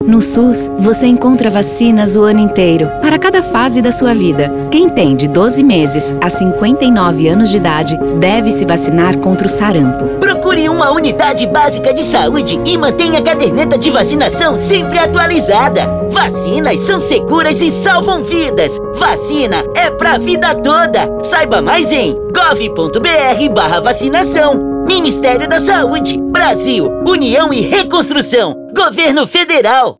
Áudio - Spot 30s - Vacinação Sarampo - 1.14mb .mp3 — Ministério da Saúde